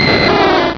pokeemerald / sound / direct_sound_samples / cries / dragonair.aif